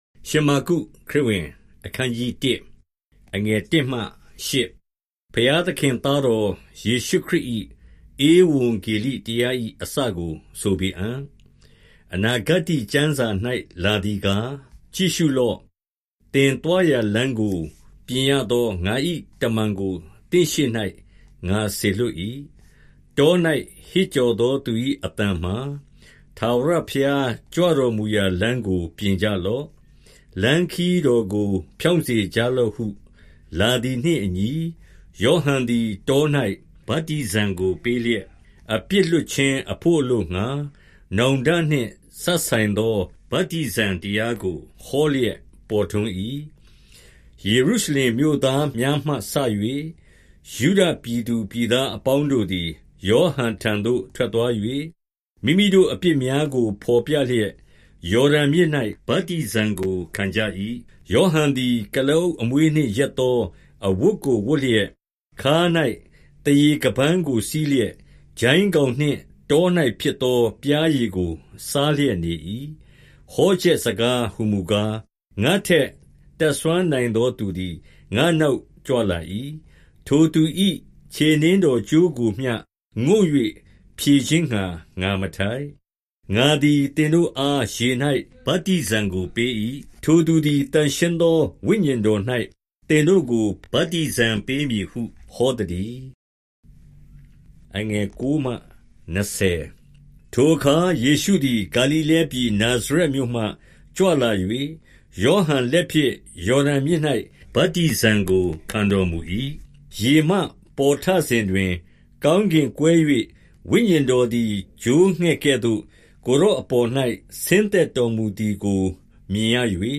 • Word for word narration
• Voice only reading